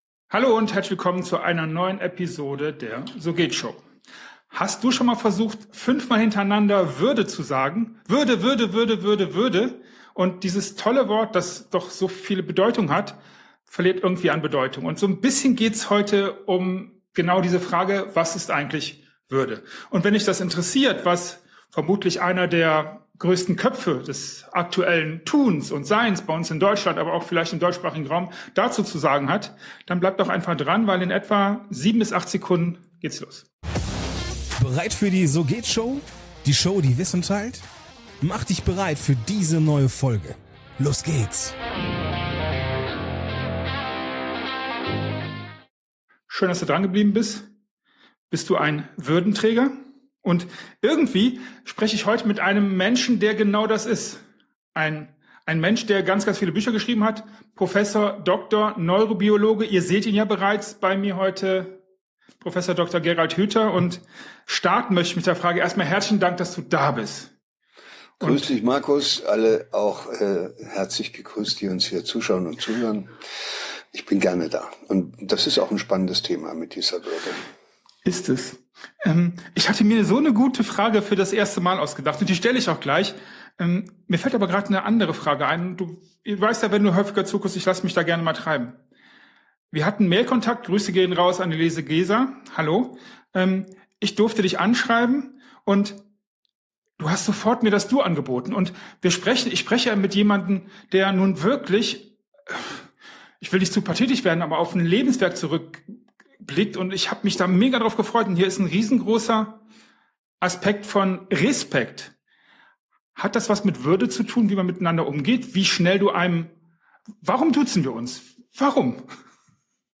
Ein Gespräch voller Aha-Momente, das dich dazu anregen wird, über deine eigene Würde nachzudenken!